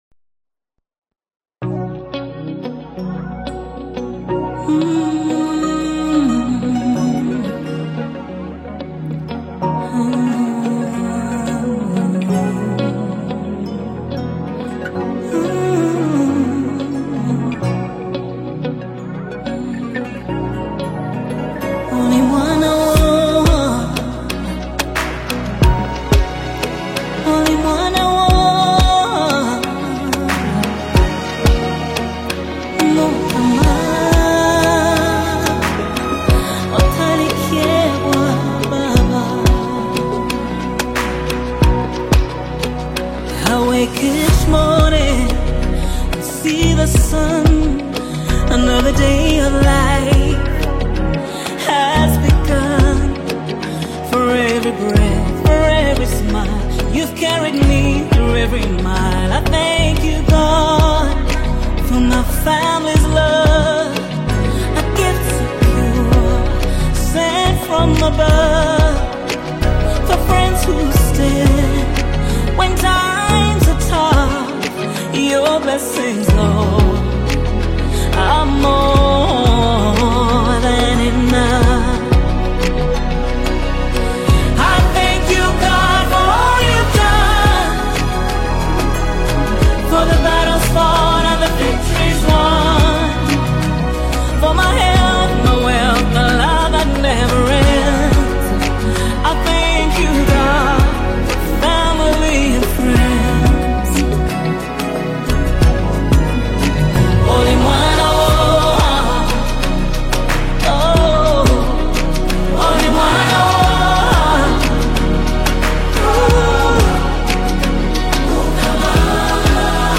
AUDIOUGANDAN SONG